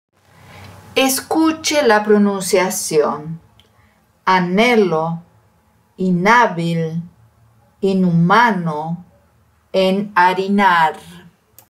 Nunca pronuncie com o som do português.
A letra “H” em espanhol não tem som.
Ouça aqui a pronúncia correta.